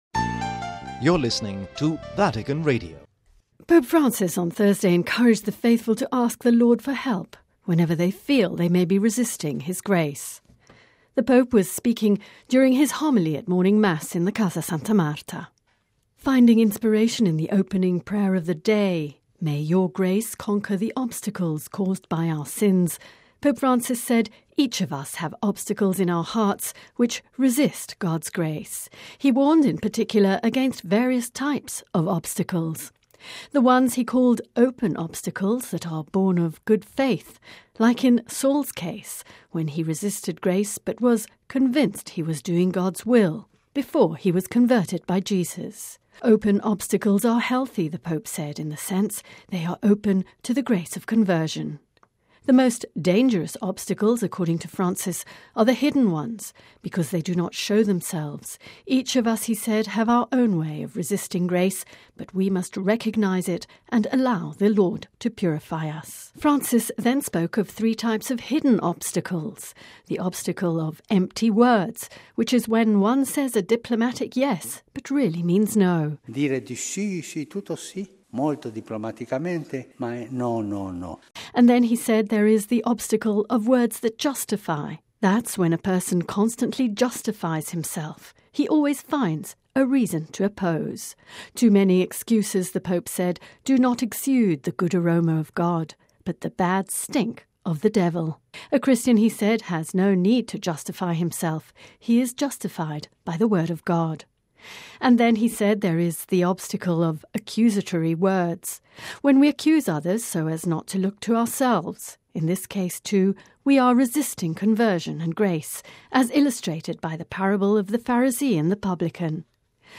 The Pope was speaking during his homily at morning Mass in Casa Santa Marta.